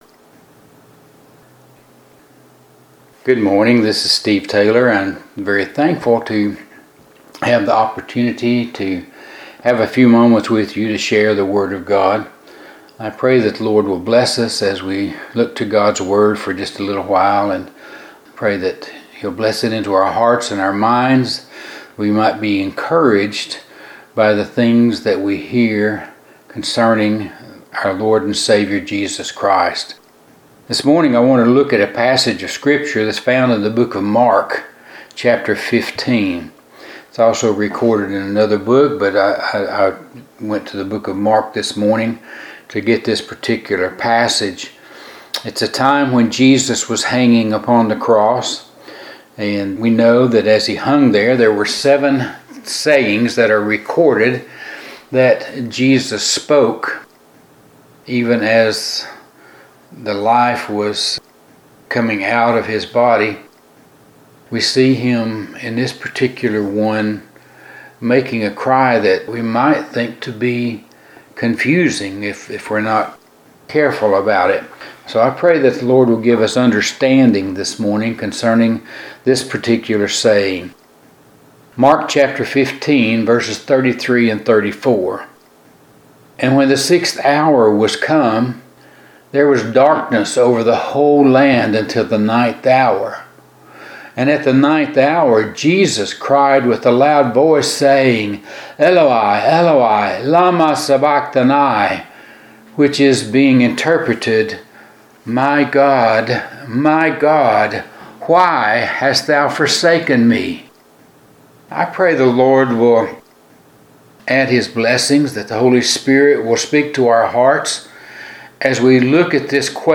Last 7 Previous Sermons